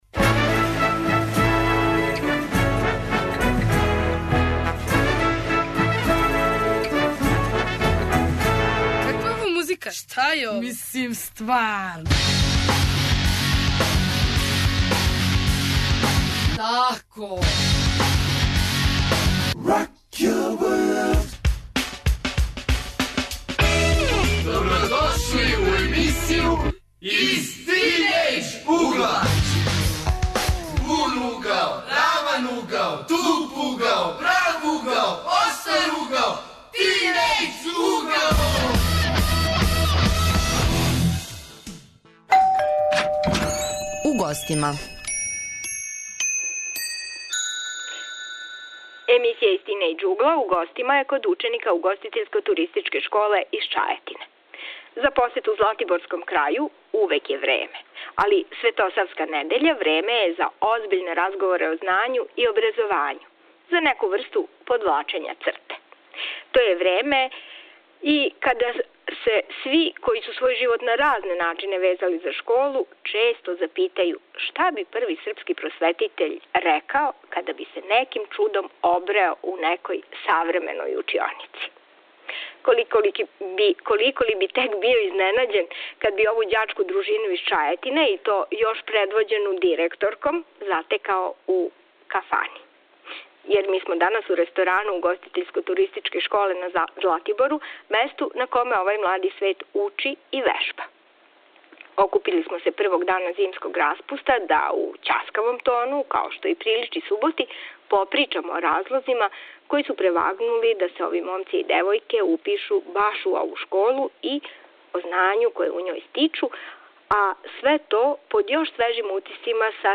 Емисија се емитује уживо из ресторана Угоститељско-туристичке школе у Чајетини где се реализује практична настава. Тема је 'Шта би рекао Свети Сава када би дошао у моју школу'.